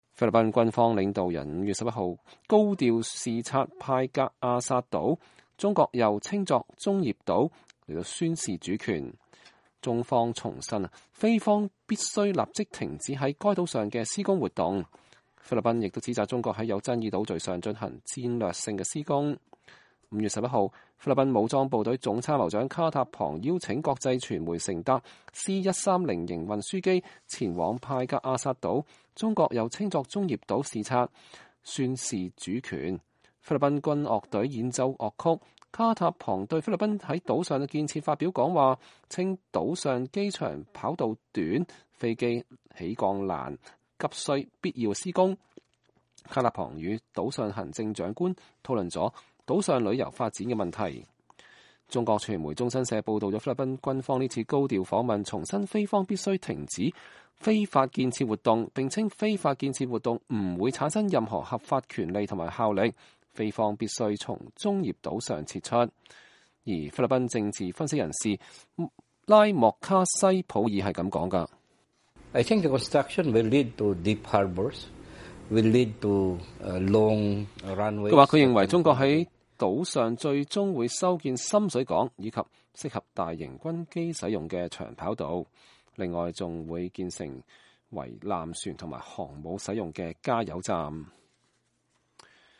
菲律賓軍樂隊演奏樂曲，卡塔龐對菲律賓在島上的建設發表講話，稱島上機場跑道短，飛機起降難，急需必要的施工。